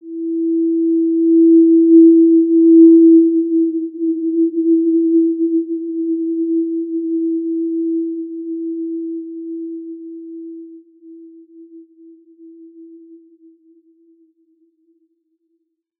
Basic-Tone-E4-mf.wav